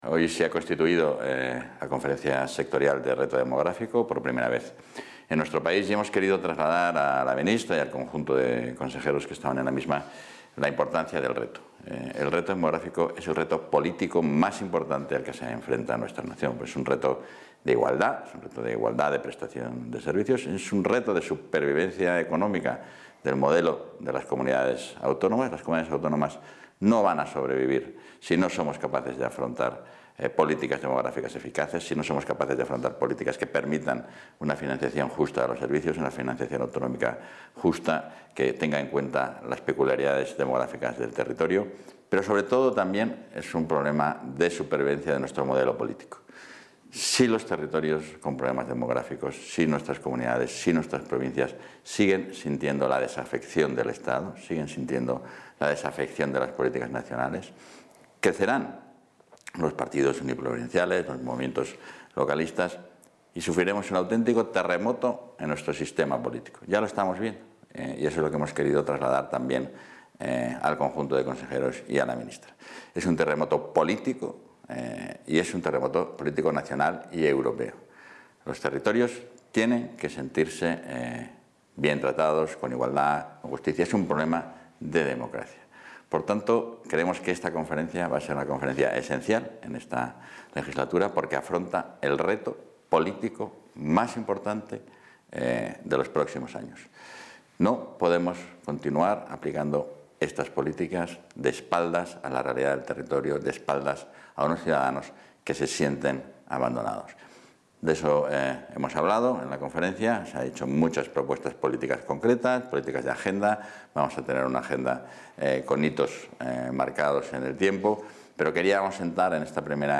Declaraciones del vicepresidente.